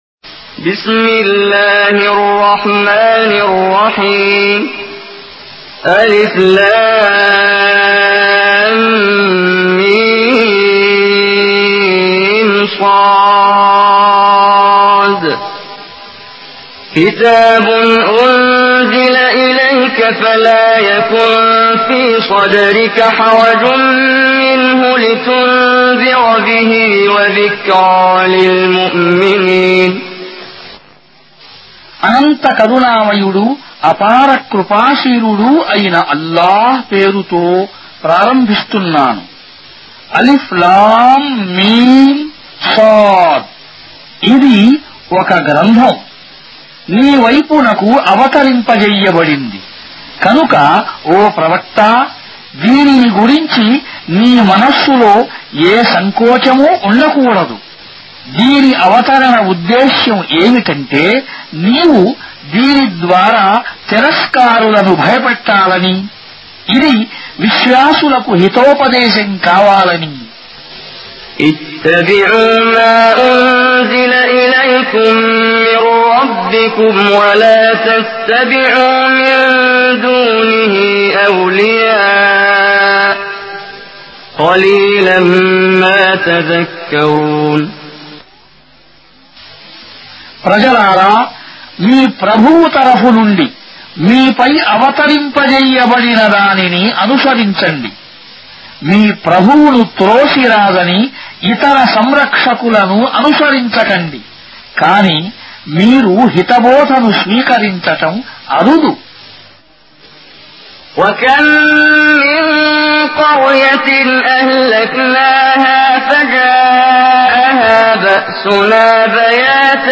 Surah Sequence تتابع السورة Download Surah حمّل السورة Reciting Mutarjamah Translation Audio for 7. Surah Al-A'r�f سورة الأعراف N.B *Surah Includes Al-Basmalah Reciters Sequents تتابع التلاوات Reciters Repeats تكرار التلاوات